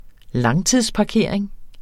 Udtale [ ˈlɑŋtiðs- ]